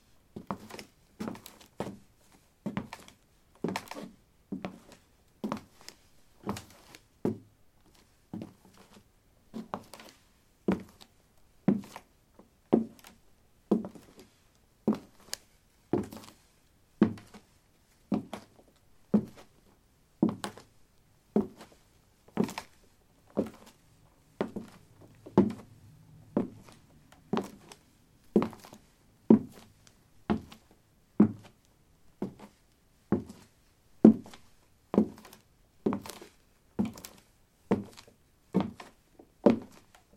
脚踏实地的木头" 木头03A拖鞋的行走
描述：走在木地板上：拖鞋。在房子的地下室用ZOOM H2记录：在混凝土地毯上放置的大木桌。使用Audacity进行标准化。
Tag: 脚步 步骤 步骤 走路 脚步 散步